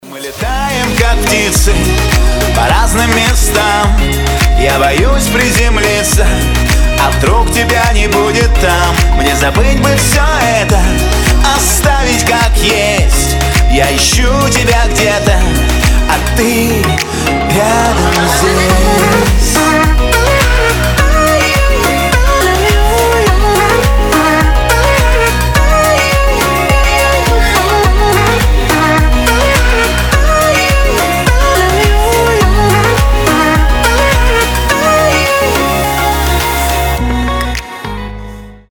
поп
романтичные